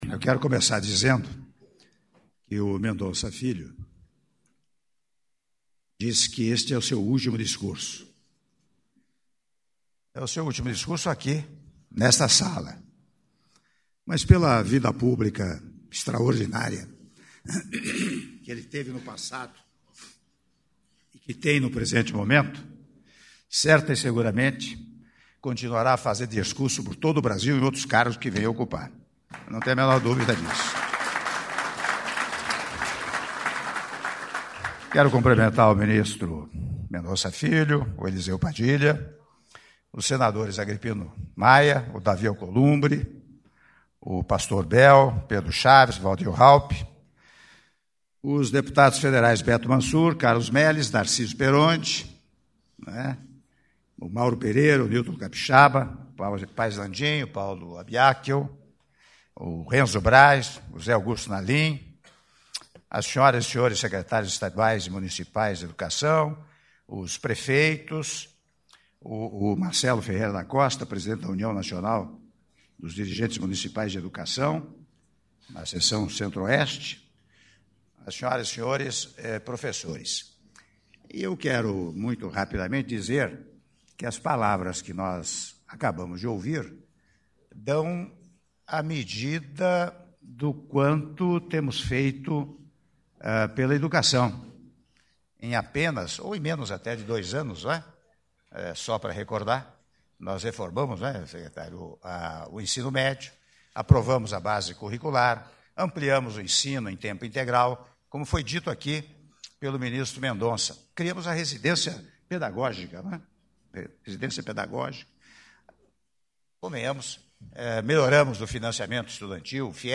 Áudio do discurso do Presidente da República, Michel Temer, na Cerimônia de Liberação de Recursos para o Programa Mais Alfabetização - (06min40s) - Brasília/DF